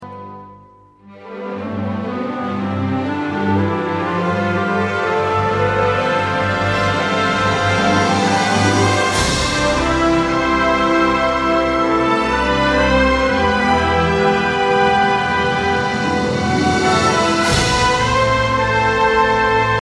本作品内容为起伏跌宕的钢琴演奏背景配乐，
该作品音质清晰、流畅，